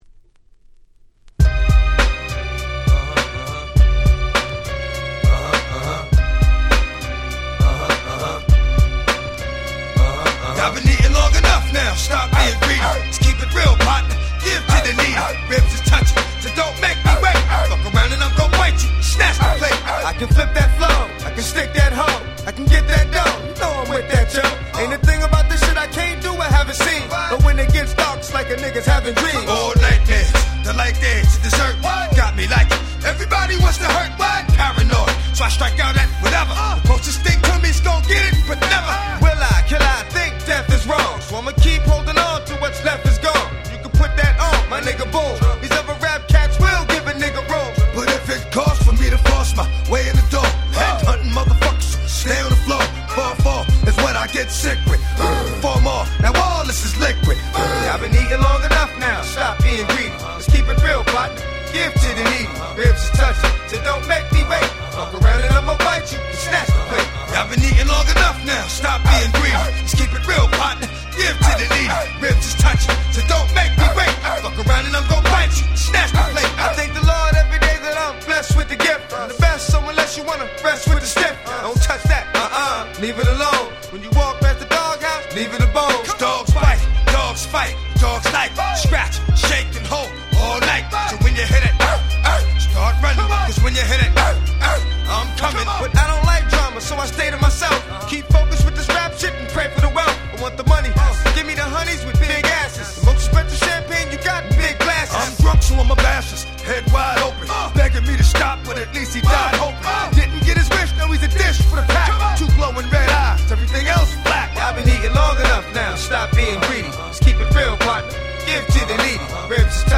【Media】Vinyl 12'' Single
※試聴ファイルは一部別の盤から録音してございます。
98' Smash Hit Hip Hop !!